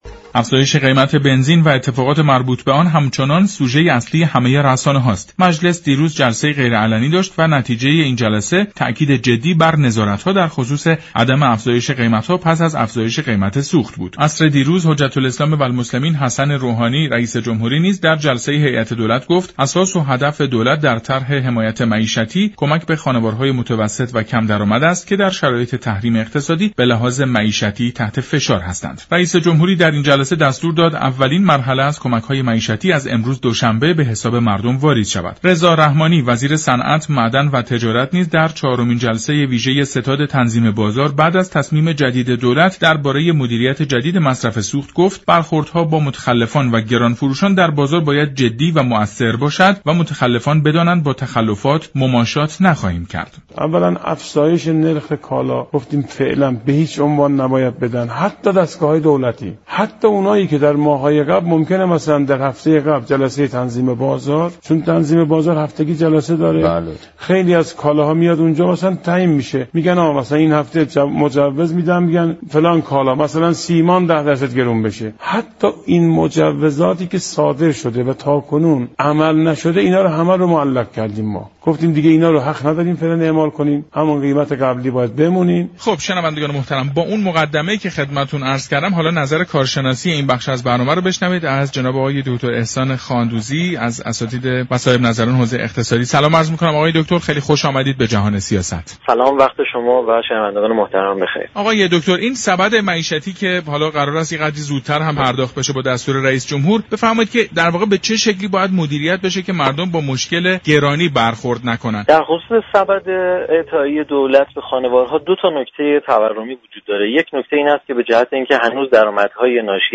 «احسان خاندوزی» كارشناس مسائل اقتصادی، در گفت و گو با برنامه «جهان سیاست» گفت:كشور باید با آگاه سازی مردم تورم انتظاری را در حد معقول خود نگه دارد.